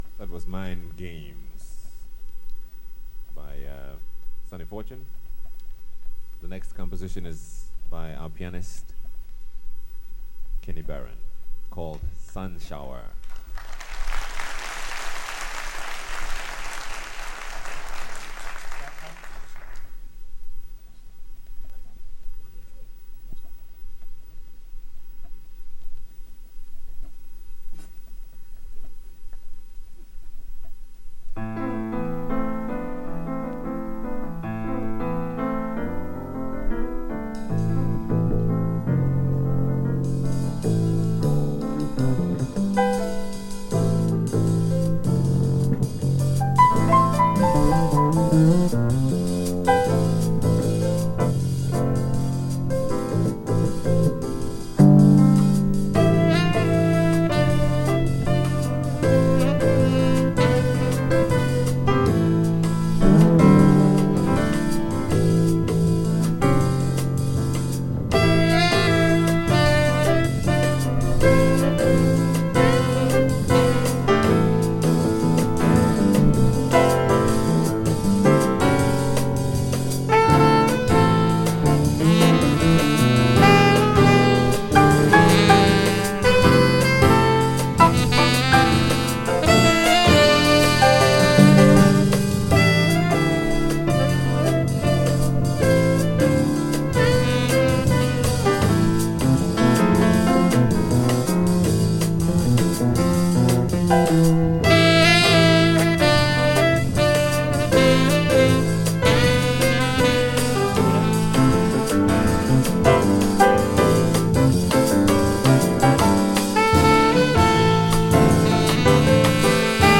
【SPIRITUAL】【FREE JAZZ】